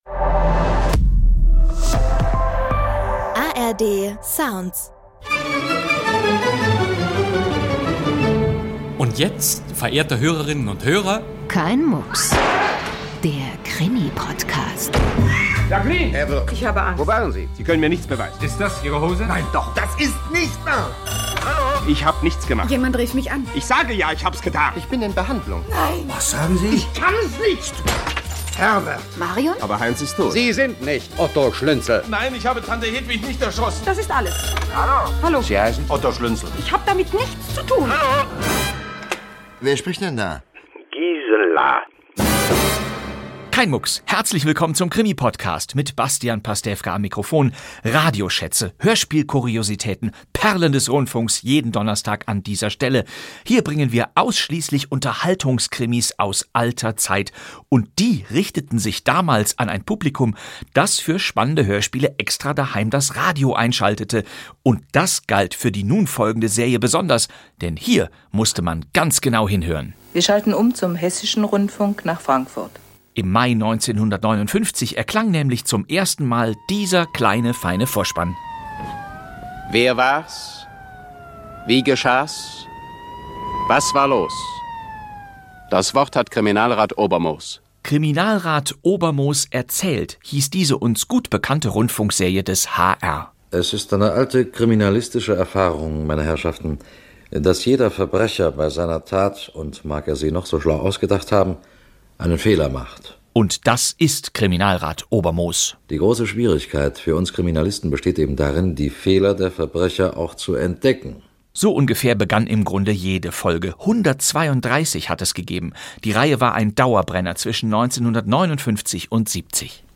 Kriminalrat Obermoos lädt in gleich zwei Hörspielen zum Mitraten ein: Im ersten Hörspiel stirbt unter mysteriösen Umständen ein Schachspieler nach einem Turnier.